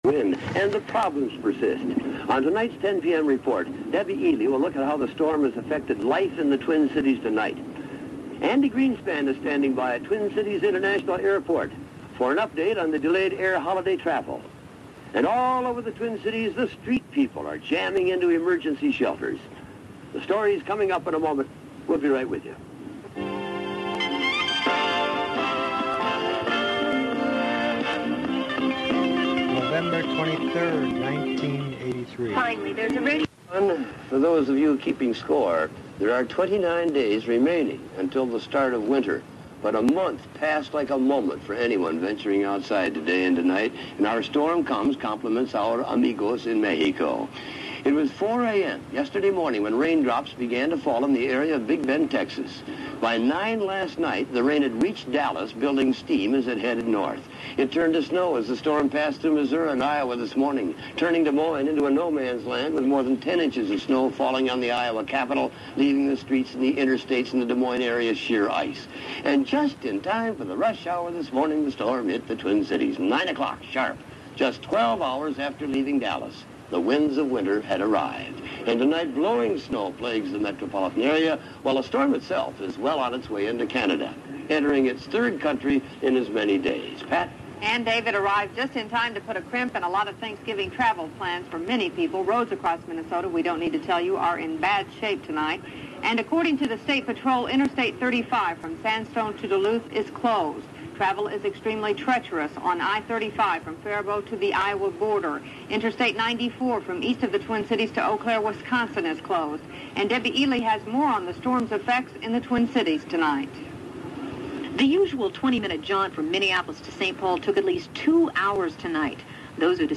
Radio report.